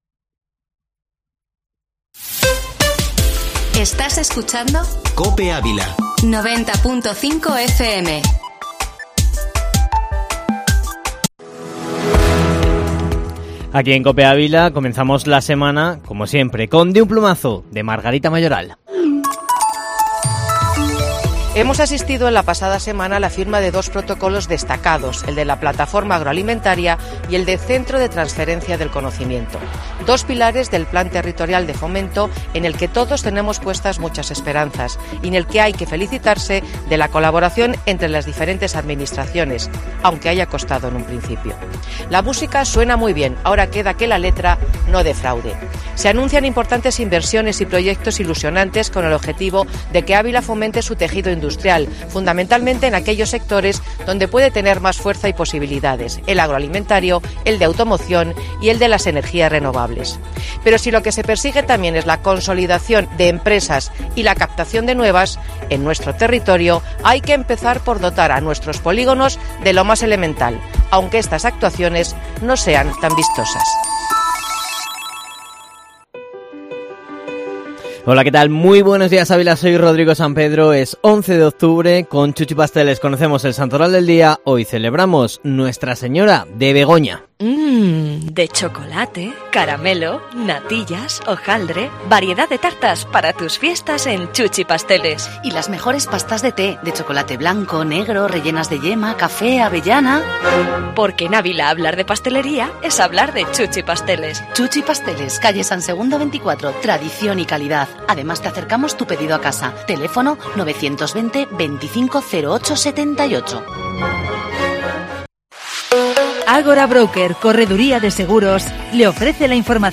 Informativo Matinal Herrera en COPE Ávila -11-oct